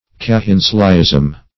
Cahenslyism \Ca*hens"ly*ism\, n. (R. C. Ch.)